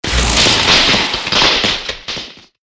LB_sparks_1.ogg